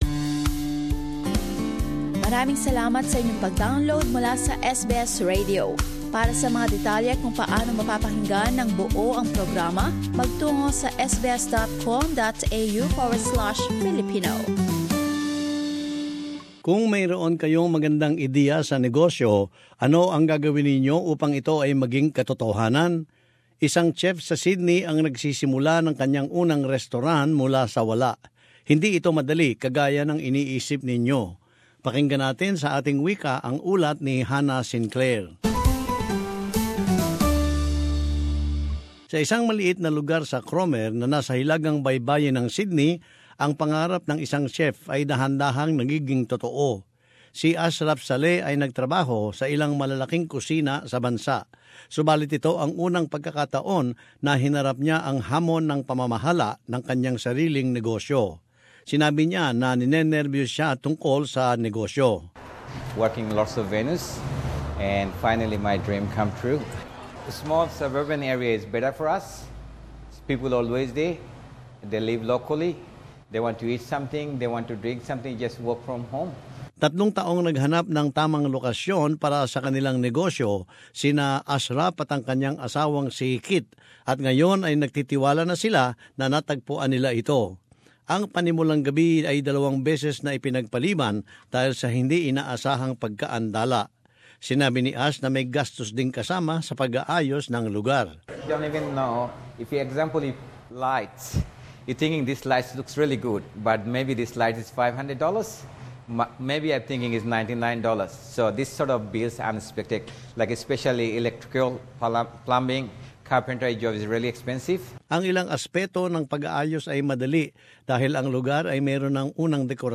As this report explains, it's not as easy as it seems.